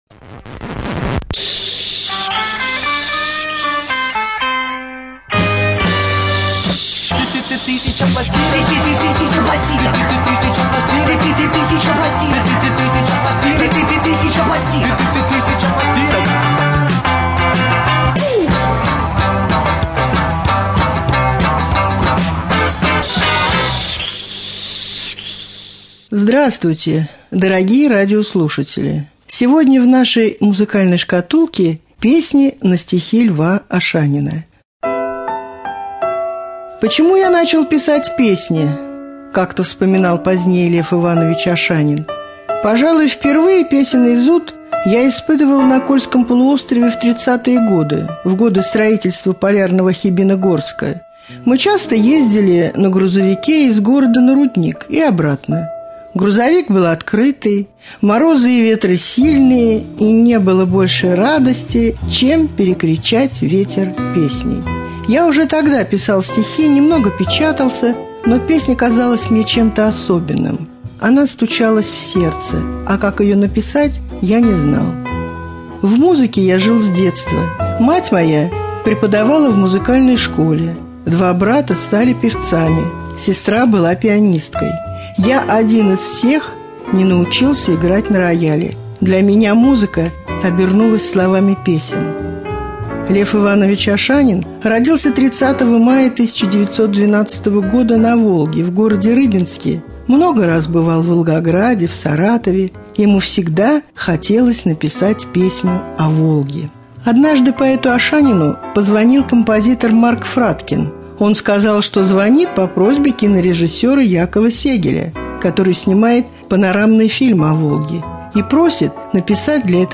12.07.2013г. в эфире раменского радио